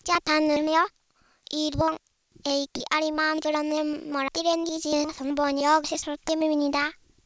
View ABSTRACT   By mapping from the phone sequence predicted for synthesis in one language to the phone-set used to label the speech of another, we can produce foreign-language speech using the voice of any speaker. In these examples we use the voice of a small Japanese child to speak in English ([SOUND 0024.03.WAV][SOUND 0024.04.WAV] greeting) and Korean ([SOUND 0024.05.WAV] [SOUND 0024.06.WAV] explaining the technical processing within CHATR).